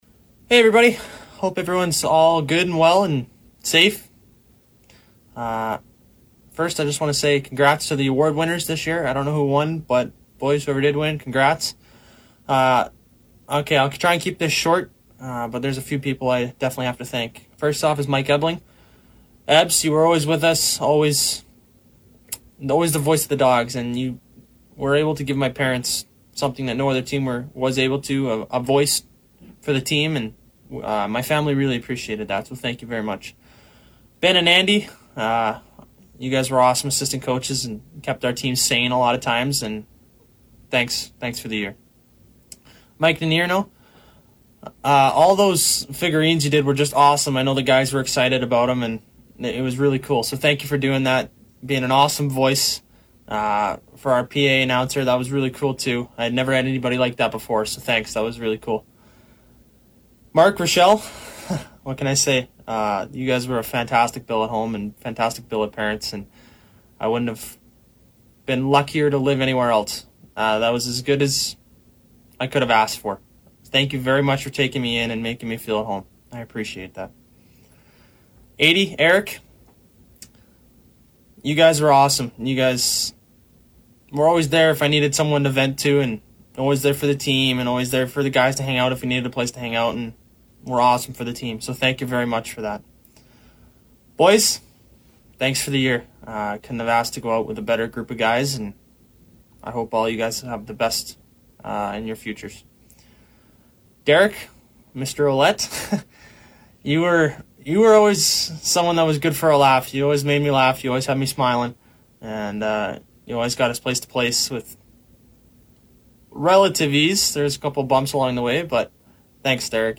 year-end speech